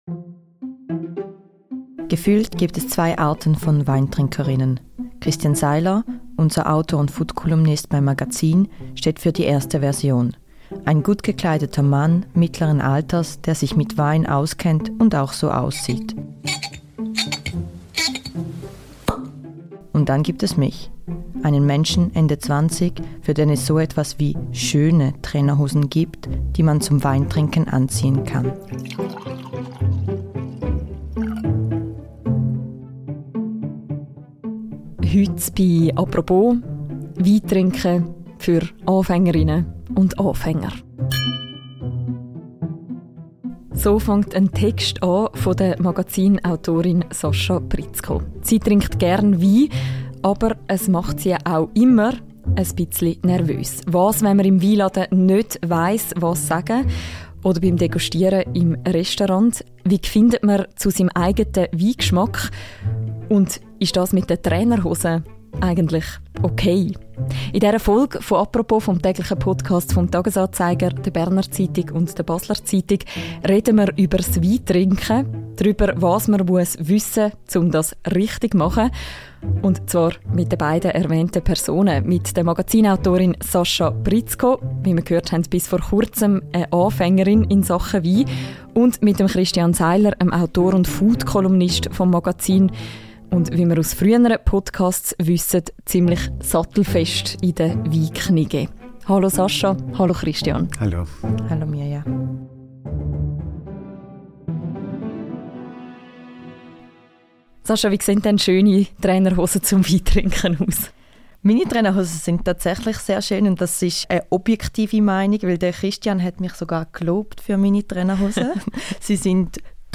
Ein Podcast-Gespräch über guten Geschmack, das richtige Glas und den «sauren Görps».